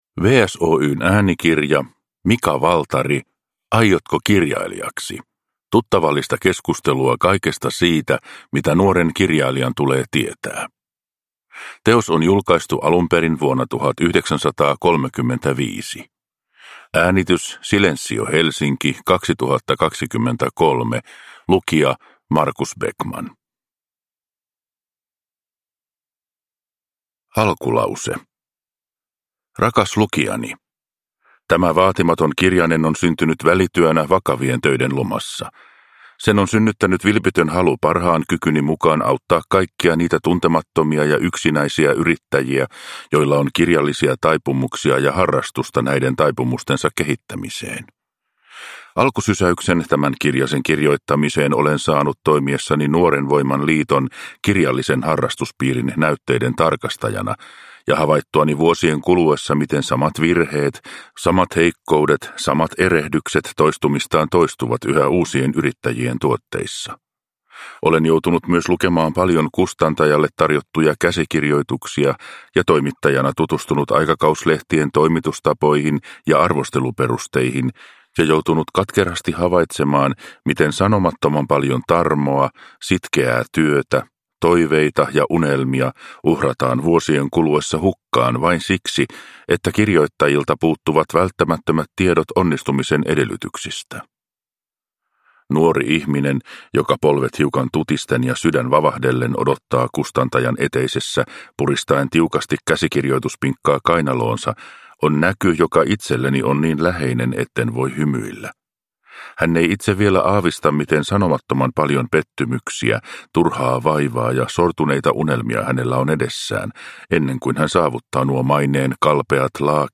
Aiotko kirjailijaksi? – Ljudbok – Laddas ner